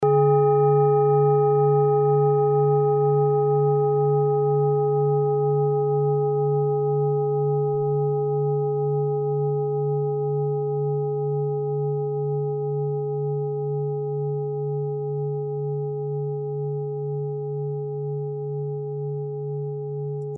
Klangschale TIBET Nr.39
Sie ist neu und ist gezielt nach altem 7-Metalle-Rezept in Handarbeit gezogen und gehämmert worden..
(Ermittelt mit dem Filzklöppel)
klangschale-tibet-39.mp3